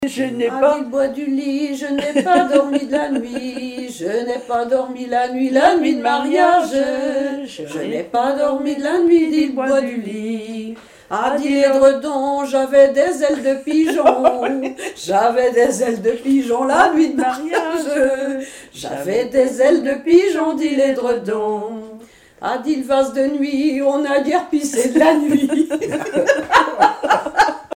Genre énumérative
Chansons et commentaires
Pièce musicale inédite